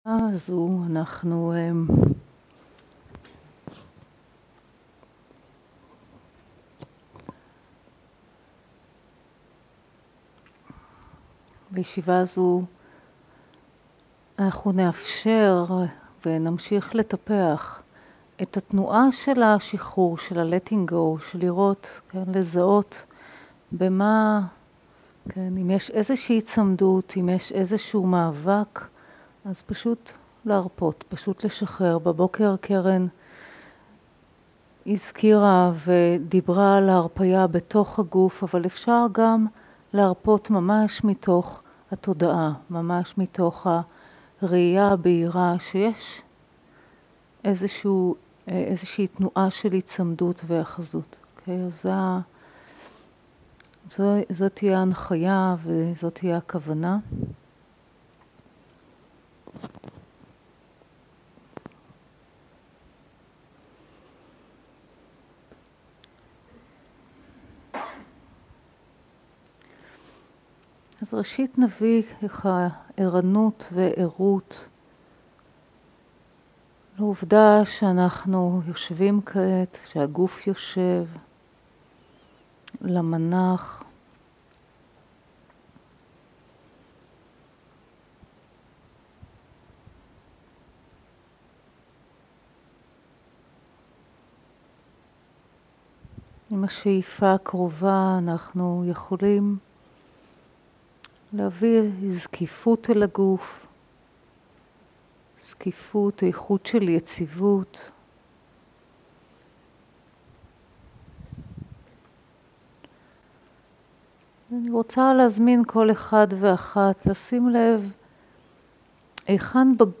מדיטציה מונחית
עברית איכות ההקלטה: איכות גבוהה מידע נוסף אודות ההקלטה